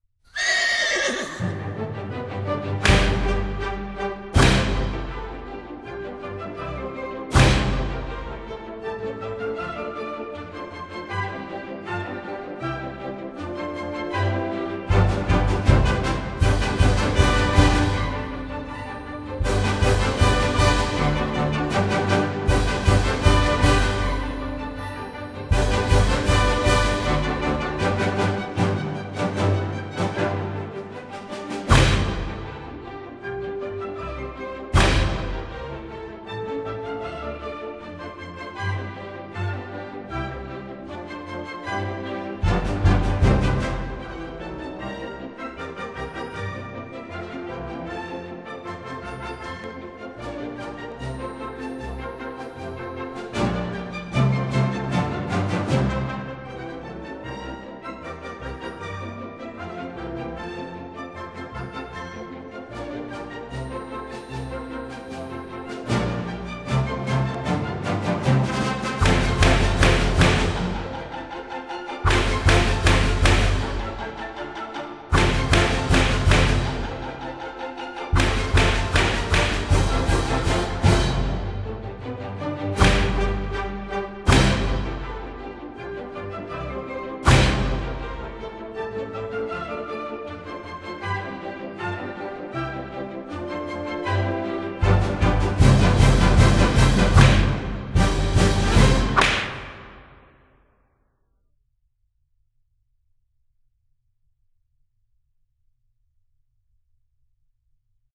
第二集仍然保持了上一集的一贯作风，录音动态大、音场宽广、立体感强烈，并继续根据音乐
又用了马嘶声、马鞭声来烘托音乐气氛，使乐曲达到美不胜收的意境。
欢快、跳跃的乐曲，趣味、真实的音效，使这些唱片自始至终充满了欢乐的节庆氛围和十足的娱乐性，
片中所有的效果声，都是用真实的声音所录制，因此动态十分巨大，聆听时请先用小音量试听，以免烧坏喇叭。
（试听为低品质wma，下载为320k/mp3）